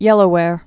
(yĕlō-wâr)